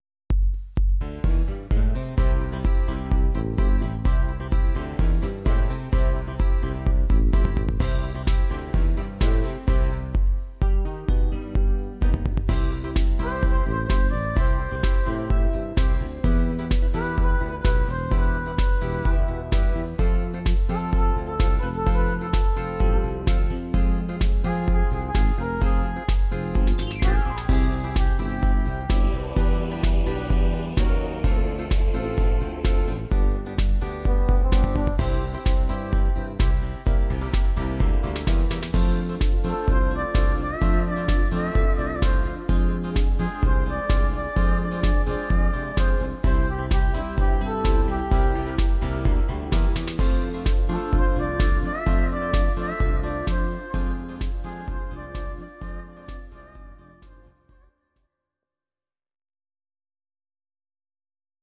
These are MP3 versions of our MIDI file catalogue.
Please note: no vocals and no karaoke included.
Your-Mix: Medleys (1041)